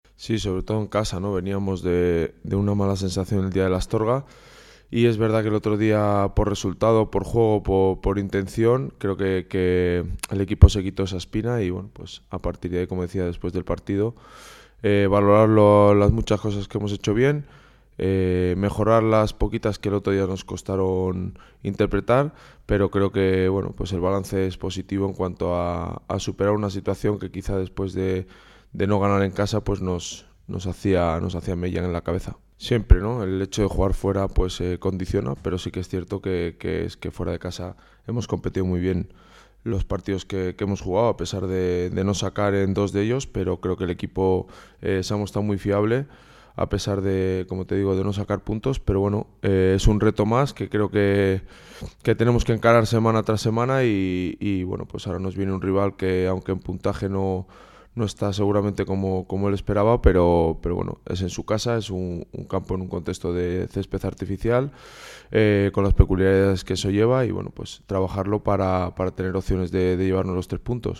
Ruedas de prensa